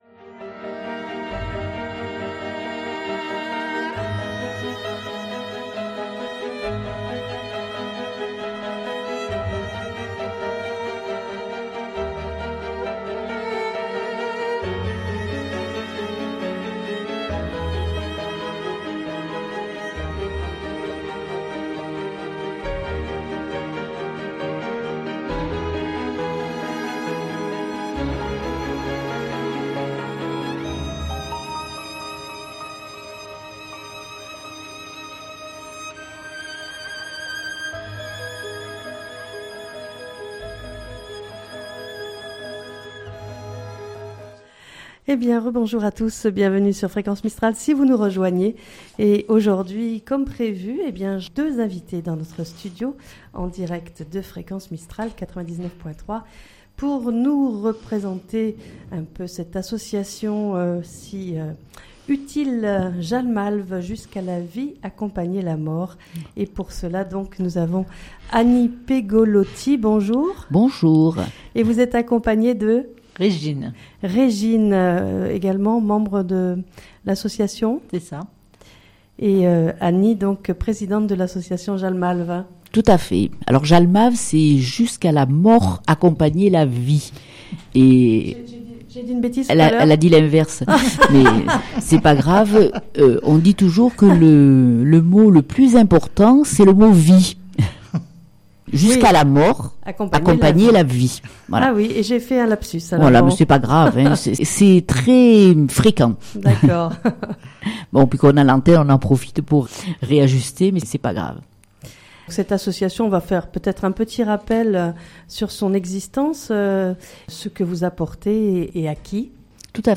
Pour nous présenter l'association JALMALV (jusqu'à la mort accompagner la vie) nos invitées sont